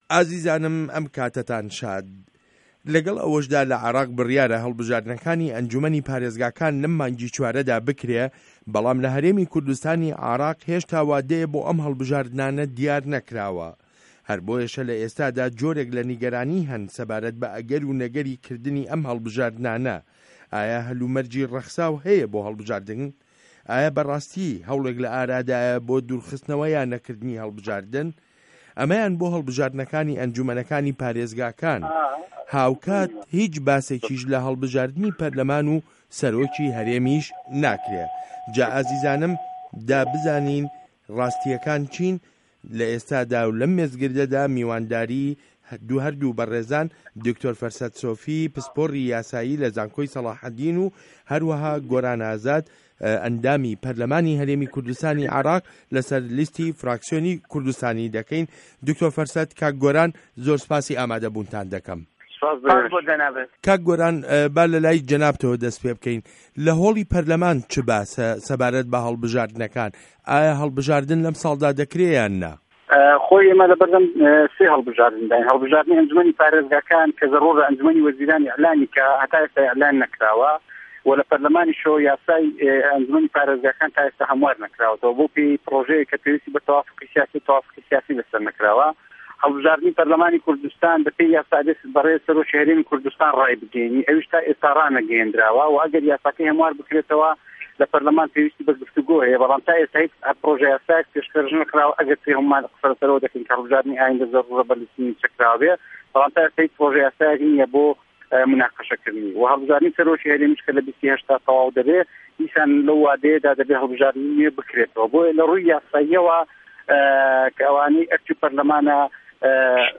مێزگرد: ئه‌گه‌ر و نه‌گه‌ری هه‌ڵبژاردن له‌ هه‌رێمی کوردستانی عێراق